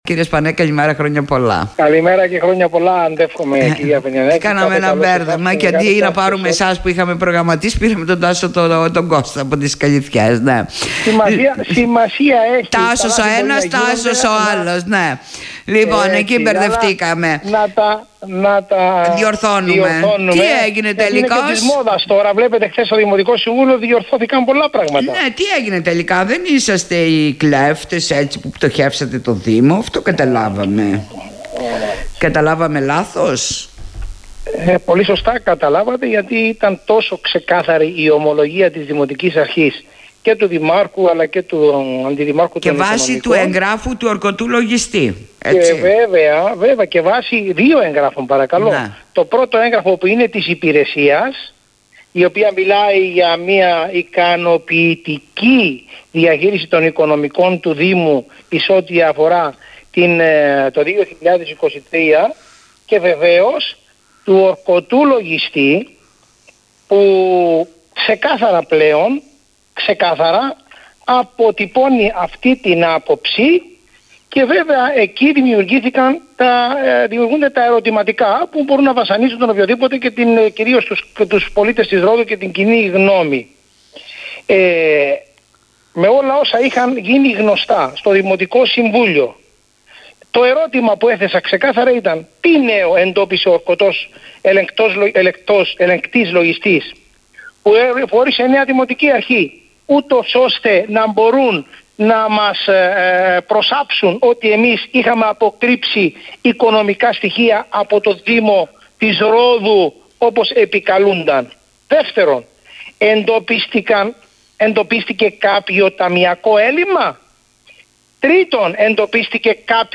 δήλωσε στον topfm
ο πρώην αντιδήμαρχος Οικονομικών και νυν δημοτικός σύμβουλος Τάσος Σπανός